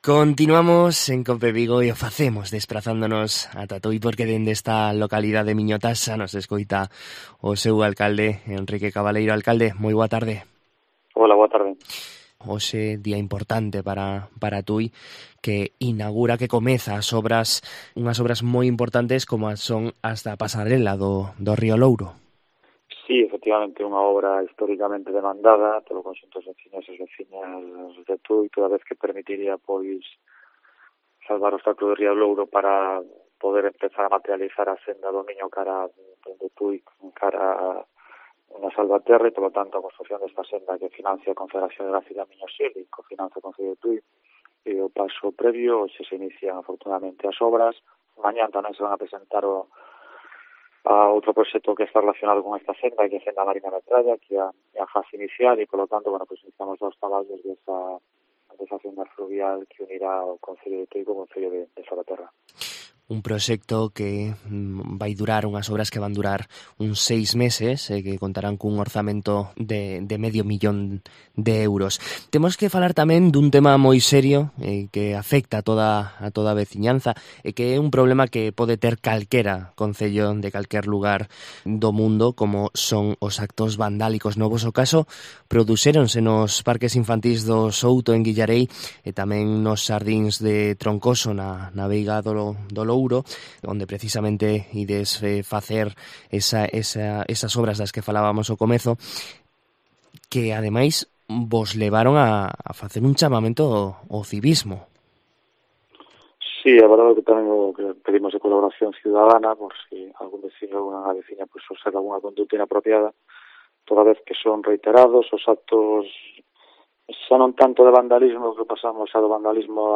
AUDIO: Coñecemos a actualidade de Tui da man do seu alcalde